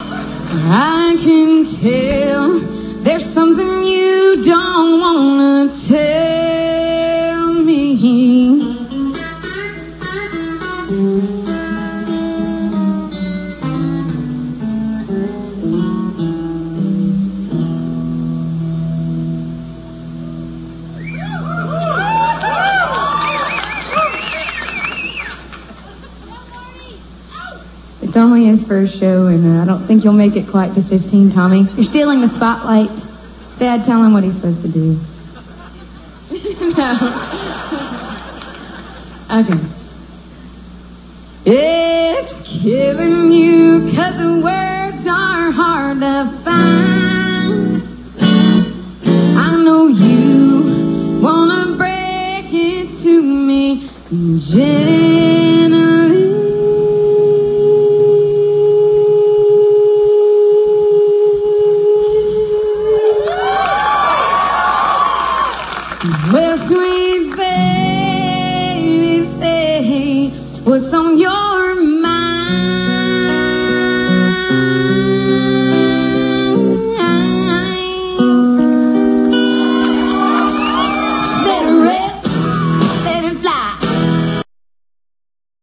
CONCERT CLIPS